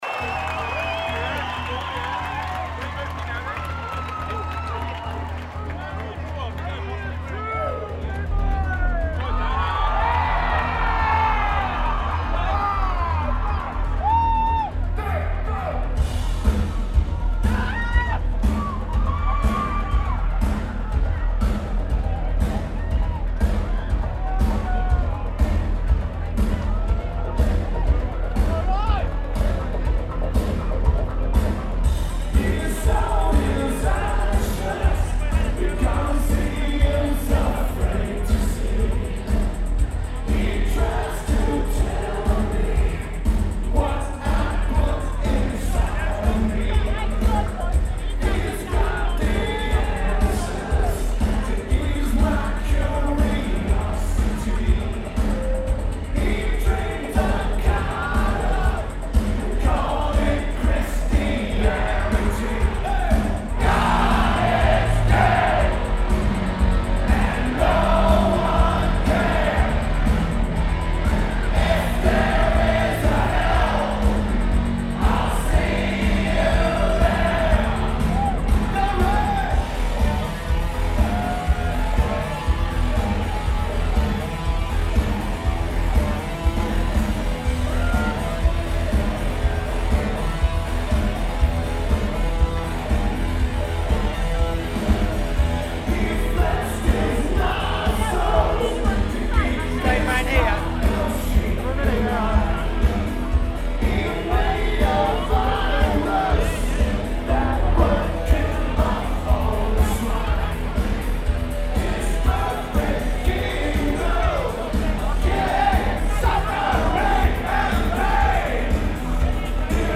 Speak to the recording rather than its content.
Civic Hall (Makeup from 03/12) Lineage: Audio - AUD (EM-8 + iRiver H140 [Rockboxed])